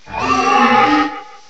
sovereignx/sound/direct_sound_samples/cries/drakloak.aif at master